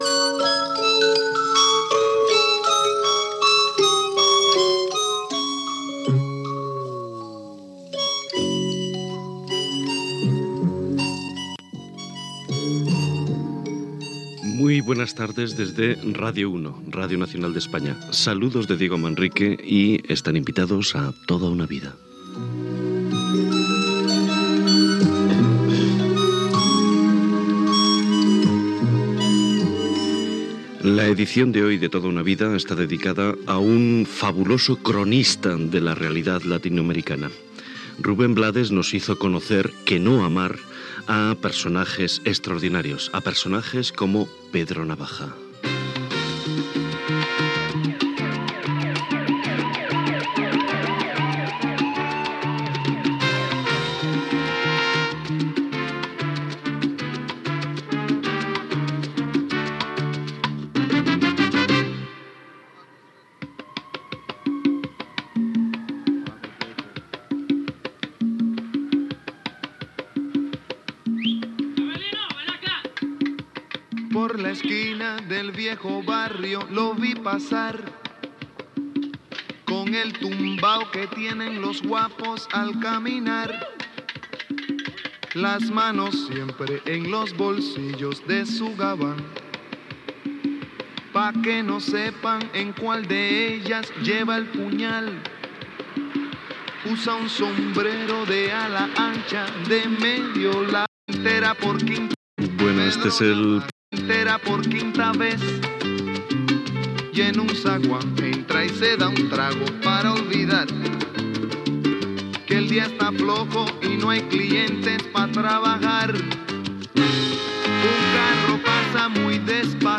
Presentació del programa i entrevista al cantant i actor Rubén Blades sobre Pedro Navaja i la seva feina d'actor i cantant.
Entreteniment